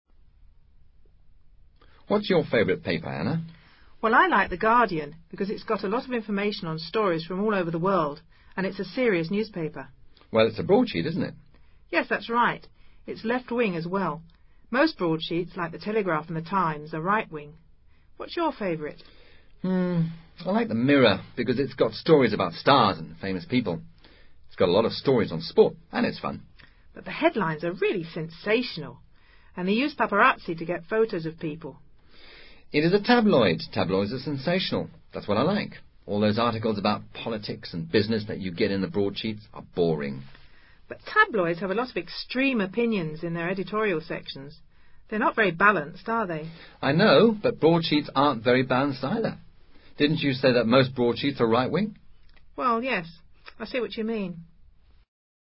Diálogo cuyo tema central son los diarios ingleses.